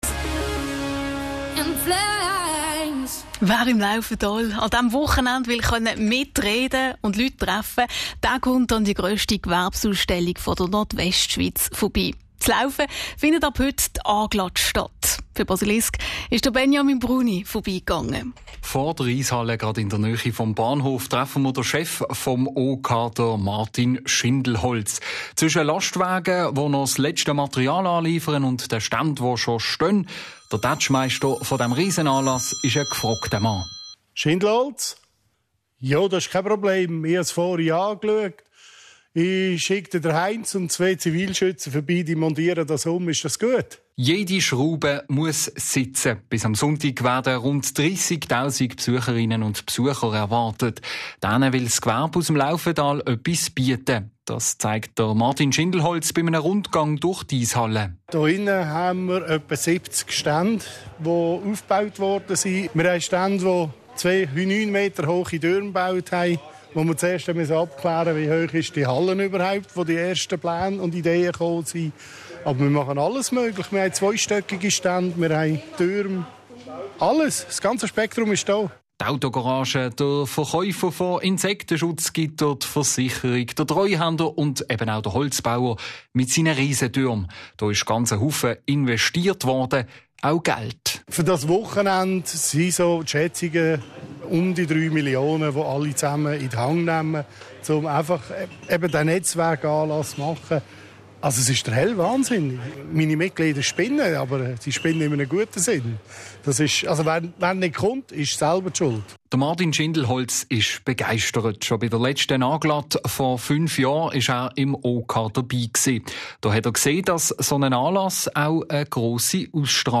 Berichterstattung Radio Basilisk I Mitschnitt mp3